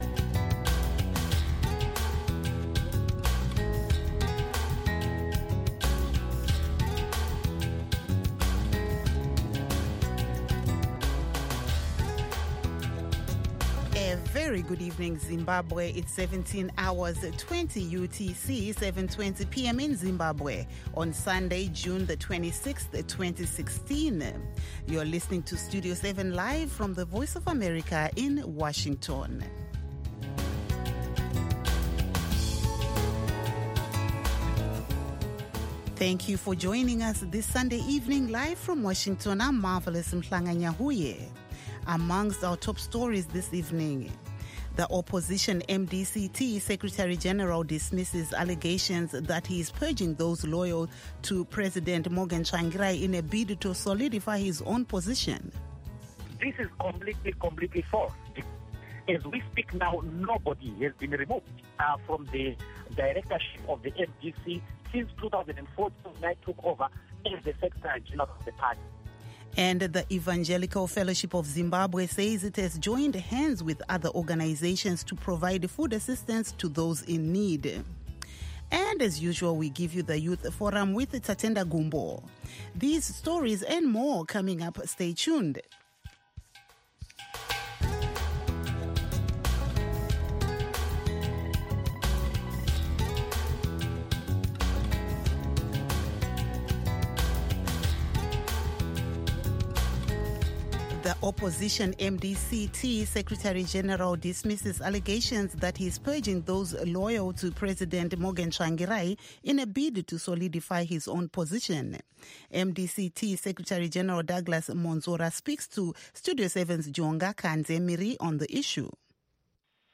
News in English